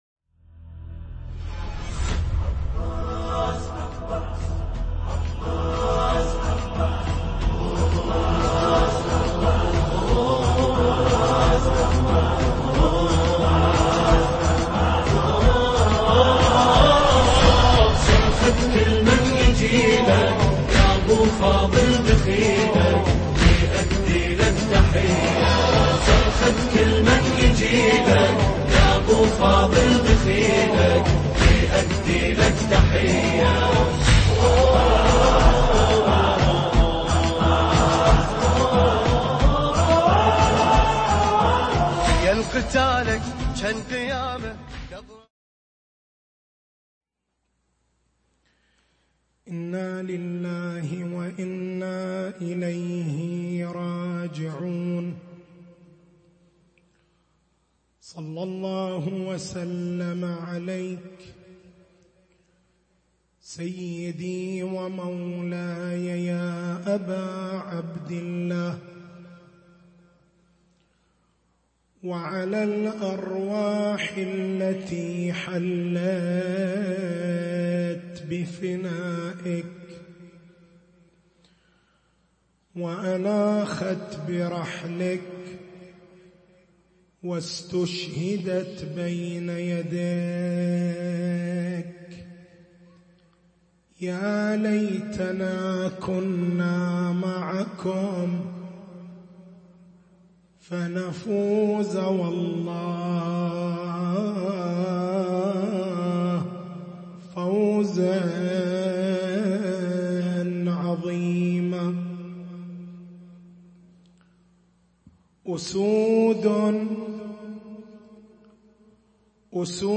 تاريخ المحاضرة: 06/01/1440 نقاط البحث: هل المجتمع بحاجة إلى علماء الدين؟ ما هي وظيفة علماء الدين؟ ماذا قدّم علماء الدين للإنسانية؟ ما هي وظيفة المجتمع تجاه علماء الدين؟